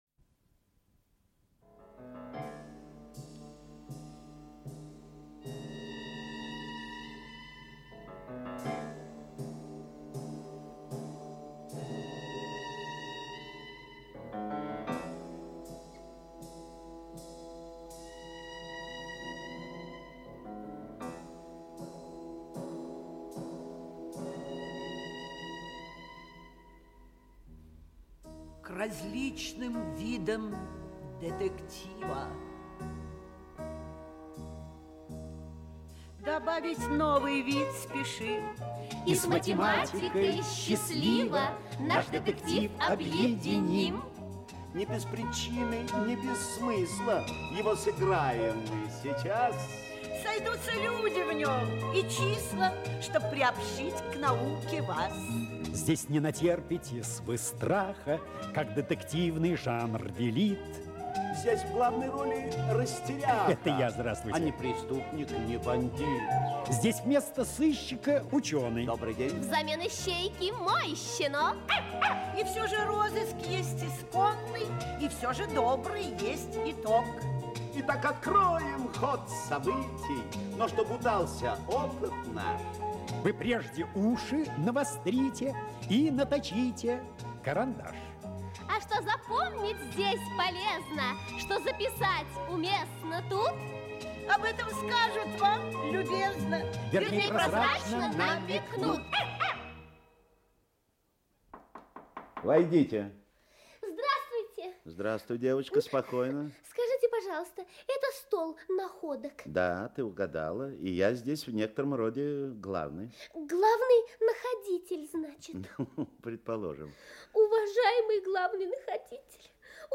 Аудиокнига Стол находок утерянных чисел - Скачать книгу, слушать онлайн